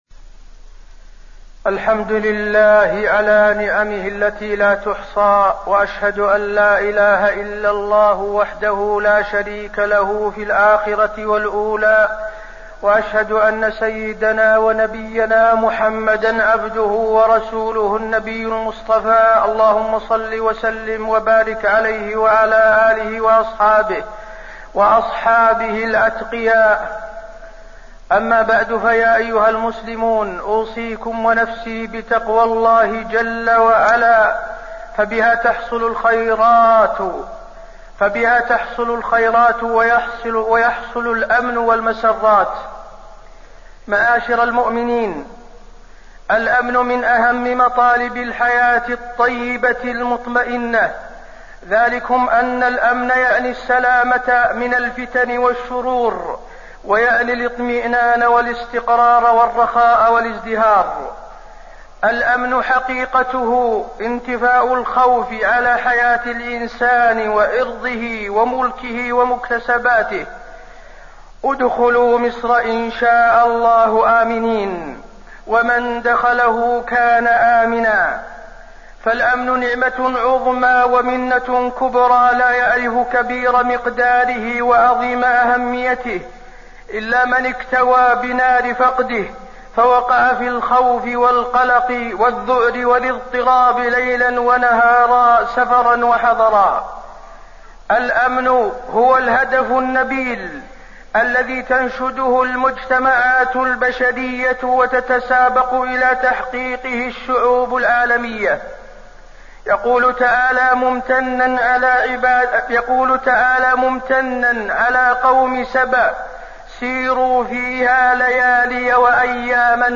تاريخ النشر ١٣ ربيع الثاني ١٤٣٢ هـ المكان: المسجد النبوي الشيخ: فضيلة الشيخ د. حسين بن عبدالعزيز آل الشيخ فضيلة الشيخ د. حسين بن عبدالعزيز آل الشيخ الأمن وأهميته في حياة المسلمين